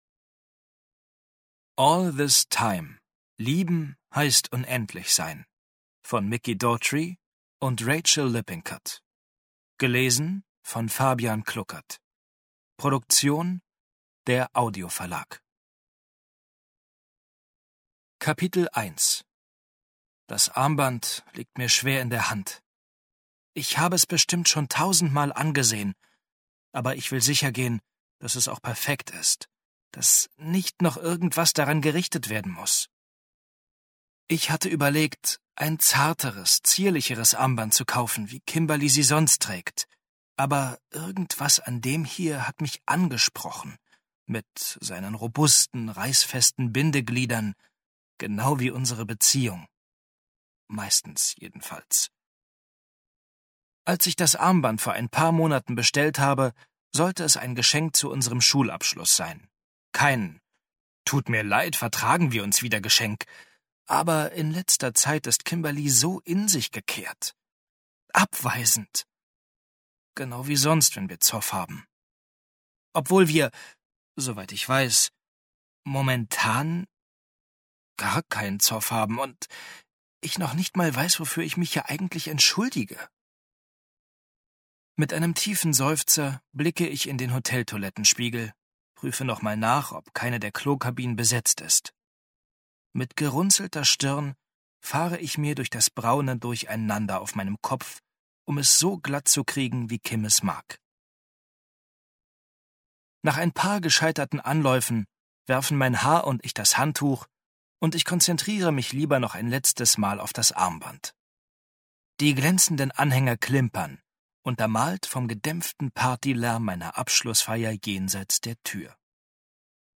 All This Time – Lieben heißt unendlich sein Ungekürzte Lesung